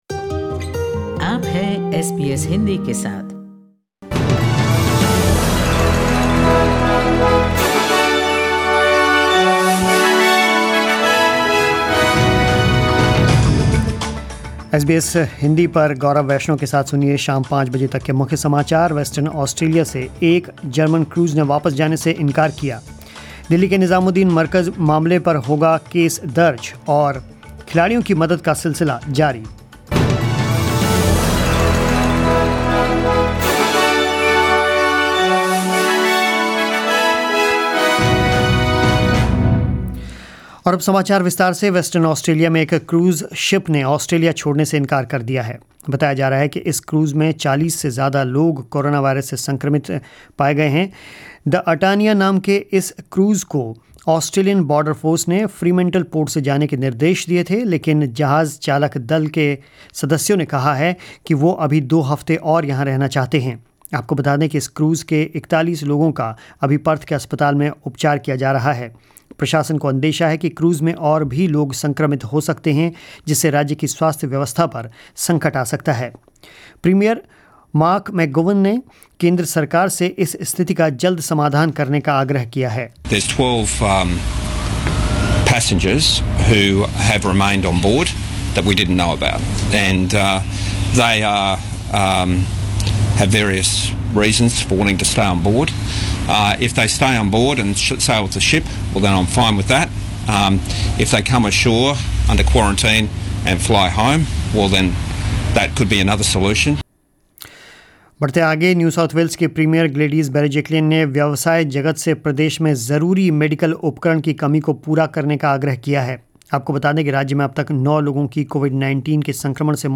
News in Hindi 01 April 2020